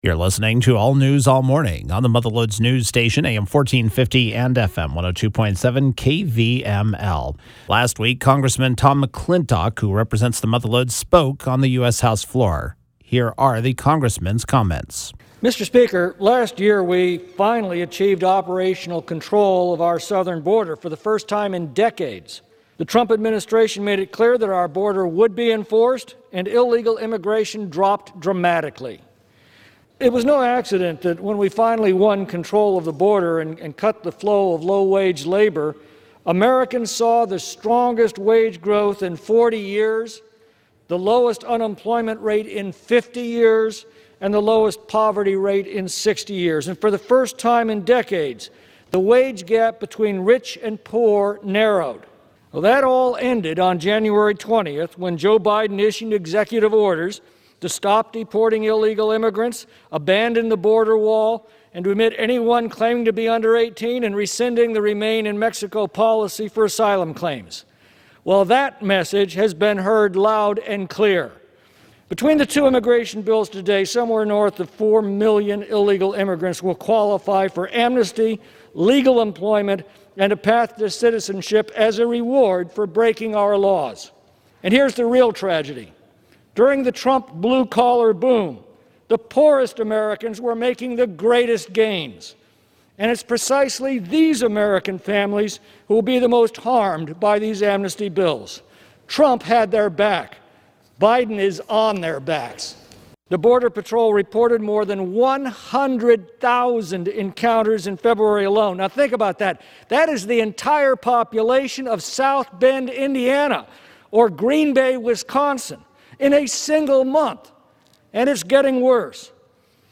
Congressman Tom McClintock recently spoke on the US House Floor.